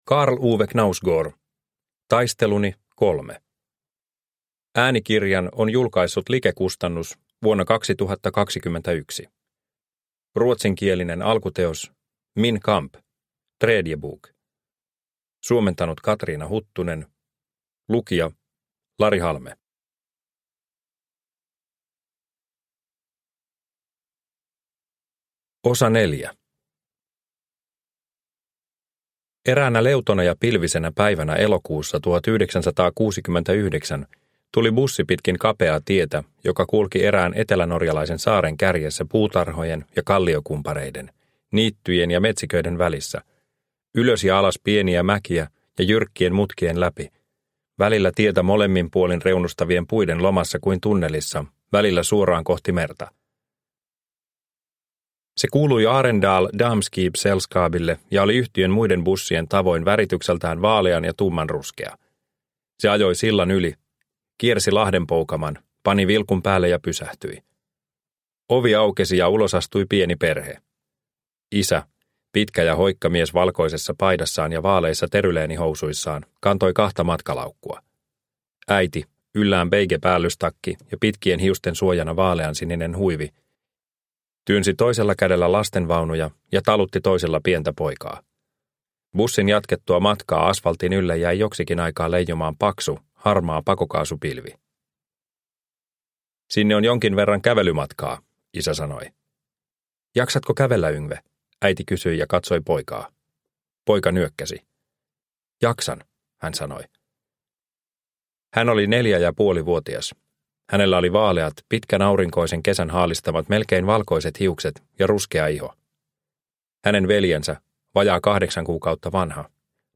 Taisteluni III – Ljudbok – Laddas ner